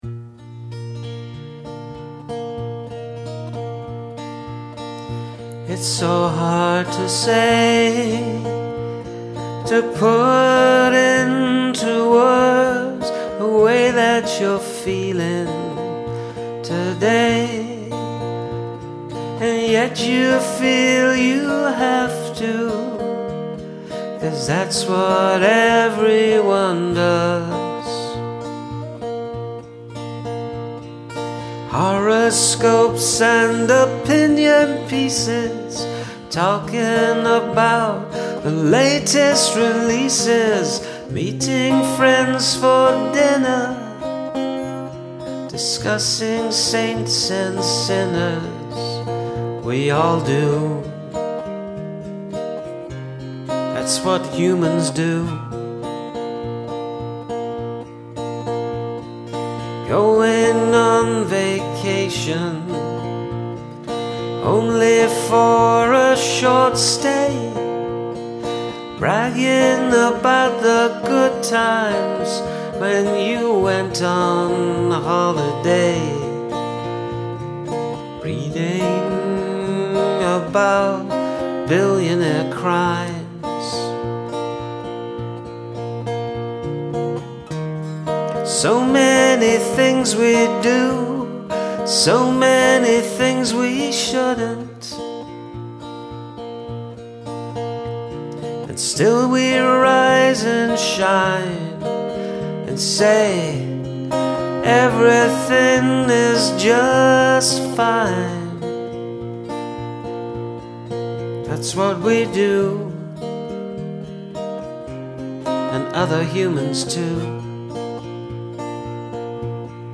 Beautiful singing and playing.
Fine laid back arrangement and vocal, perfect for these lyrics.